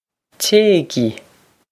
Téigí Chayg-ee
This is an approximate phonetic pronunciation of the phrase.